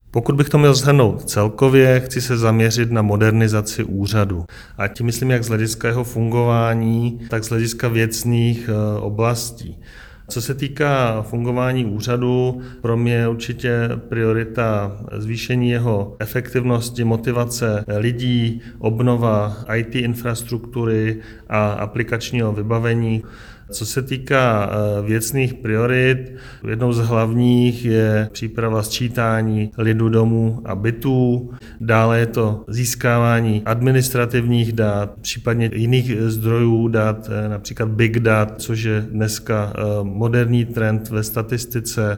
Vyjádření předsedy ČSÚ Marka Rojíčka, soubor ve formátu MP3, 1.61 MB